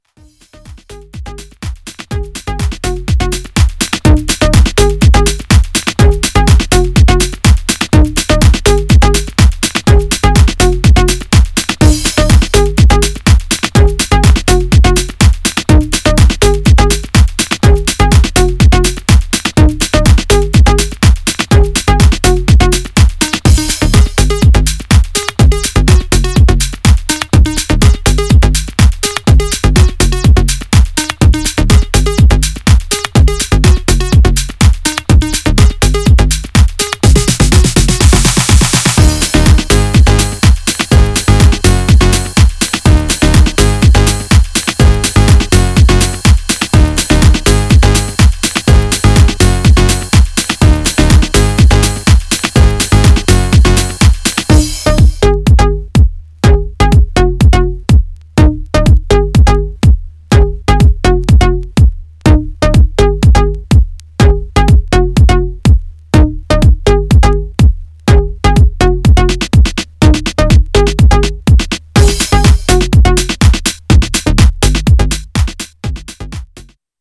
Styl: Electro, House, Techno